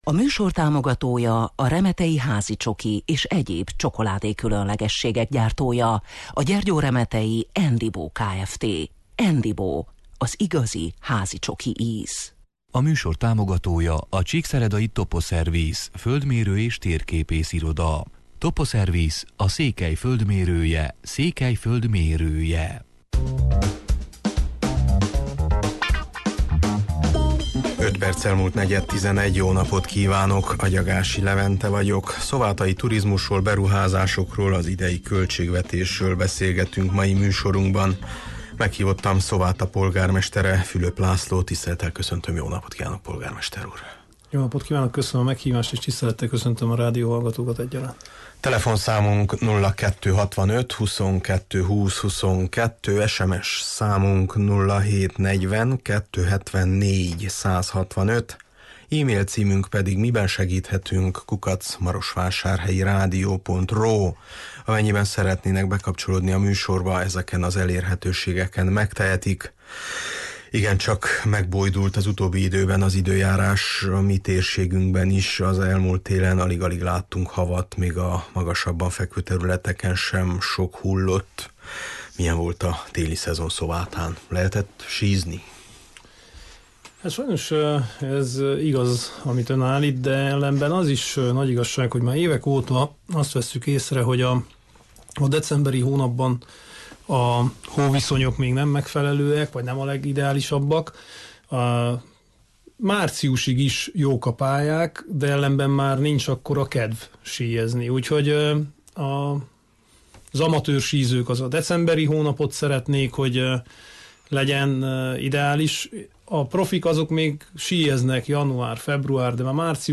Meghívottam Szováta polgármestere, Fülöp László: